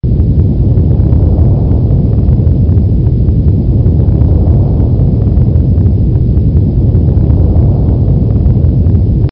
Earthquake
Bass heavy earthquake sound effects from nature. Sound of an earthquake outside. Low rumbling and such.